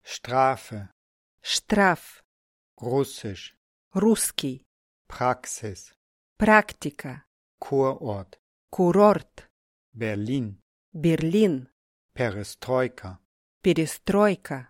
Viele Wörter klingen im Deutschen und Russischen ähnlich, unterscheiden sich aber deutlich in der R-Artikulation.
🔊 Hörprobe: Deutsche und russische Wörter im Wechsel
r-woerter-deutsch-russisch.mp3